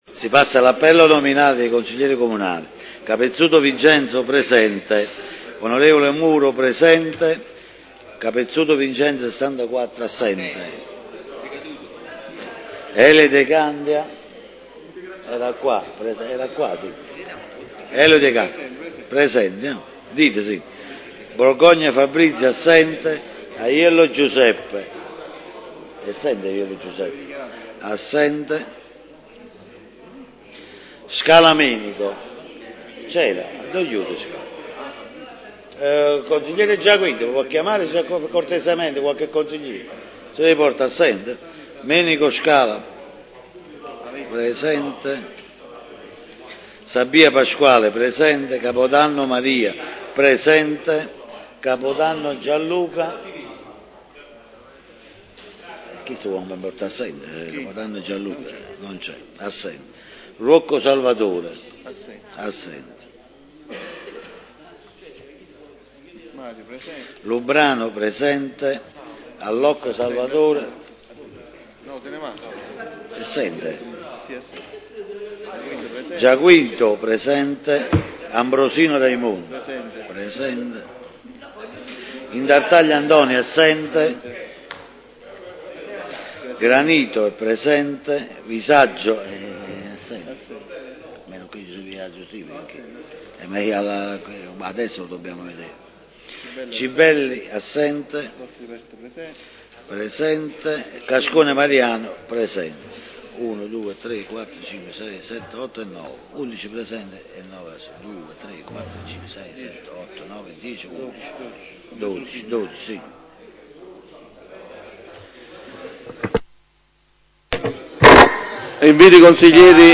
Pubblicato nella radio del Procidano la seduta del Consiglio Comunale di Procida del 23 dicembre 2014 - Il Procidano
Convocata la seduta del Consiglio Comunale di Procida.